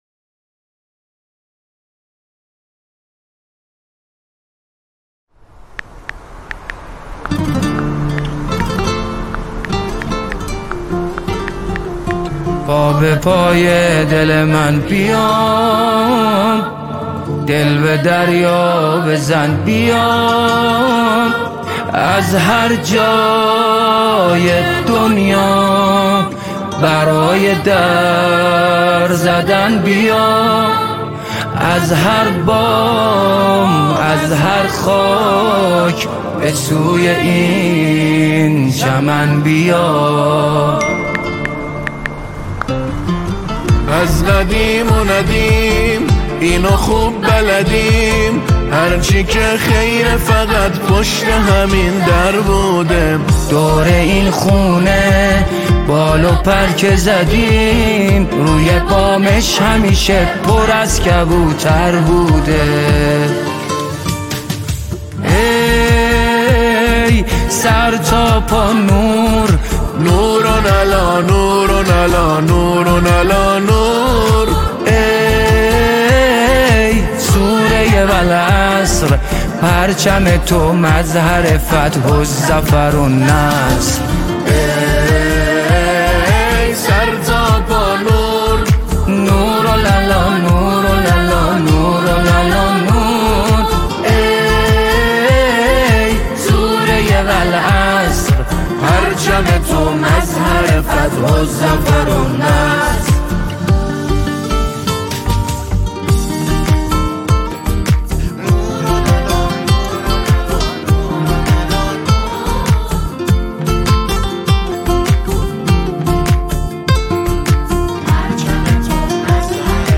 نماهنگ عربی دلنشین و احساسی